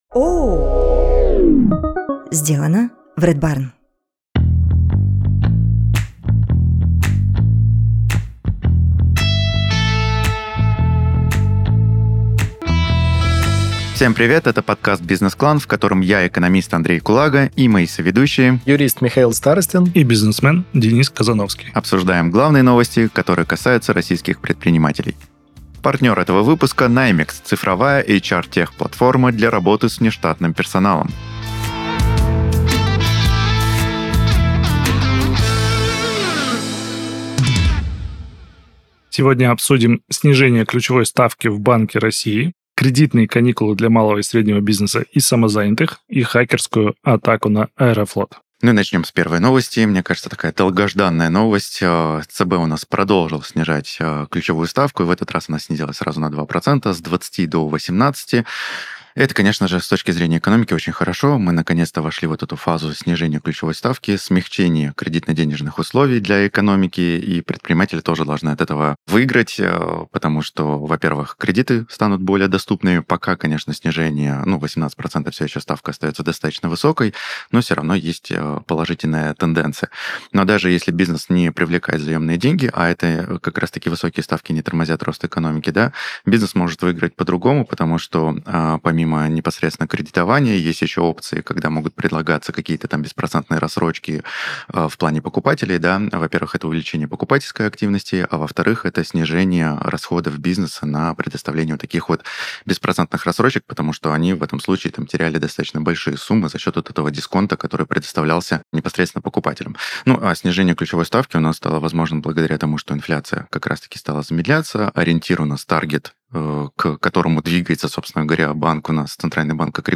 Каждую неделю экономист, юрист и предприниматель обсуждают главные новости, которые прямо или косвенно касаются российских предпринимателей.